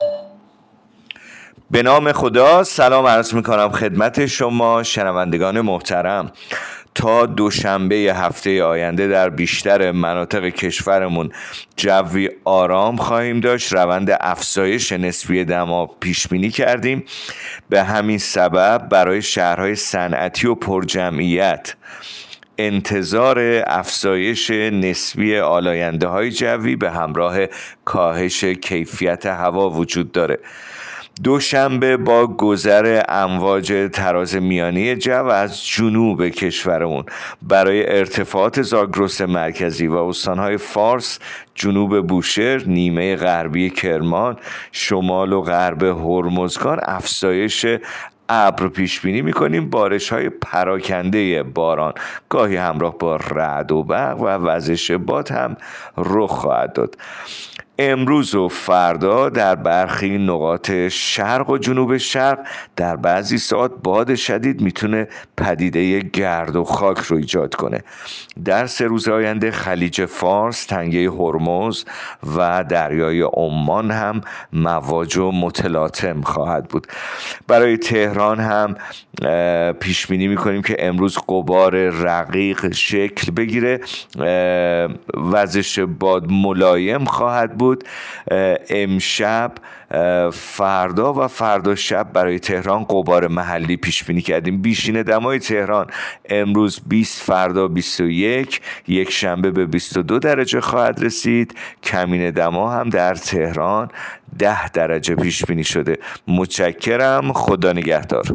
گزارش رادیو اینترنتی پایگاه‌ خبری از آخرین وضعیت آب‌وهوای ۲۴ اسفند؛